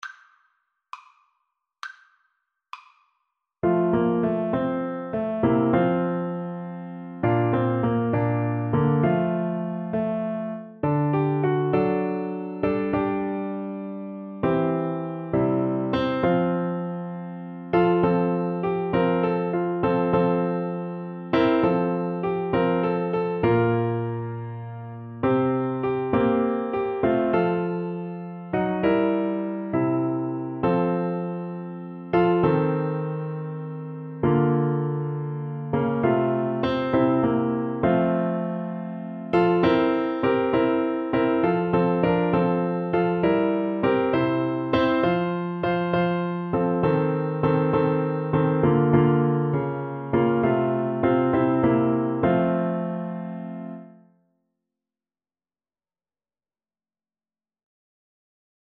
6/8 (View more 6/8 Music)
Classical (View more Classical French Horn Music)